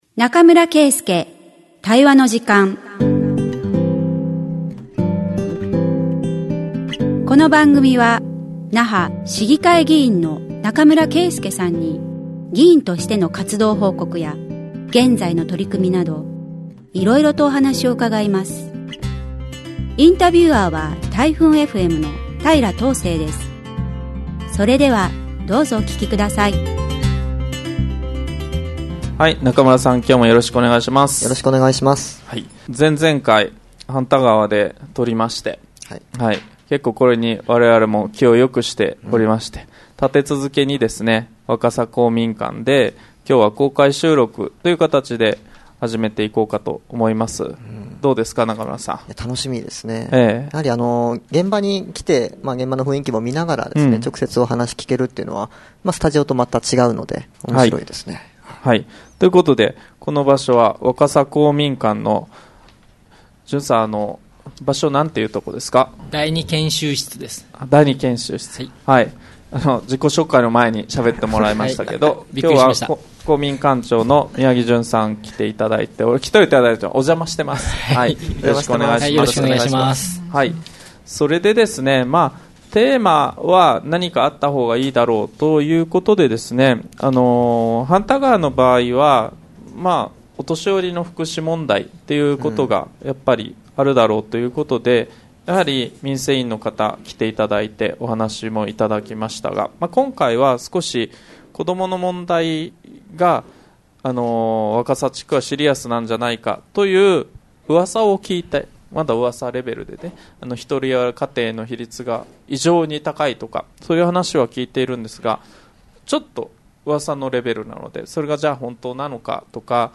【公開収録】